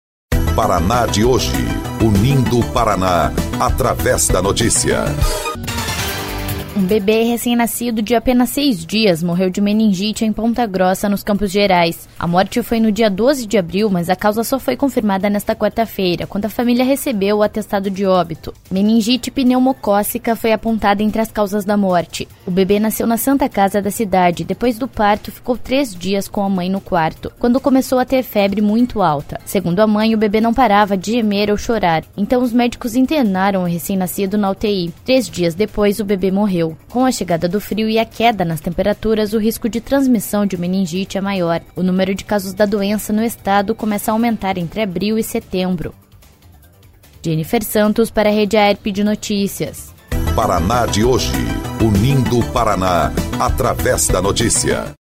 18.04 – BOLETIM – Recém-nascido morre de meningite em Ponta Grossa